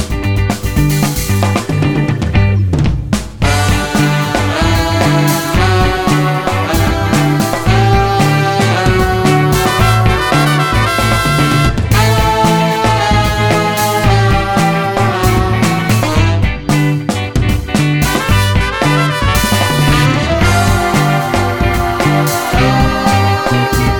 Live Version Pop (1960s) 2:57 Buy £1.50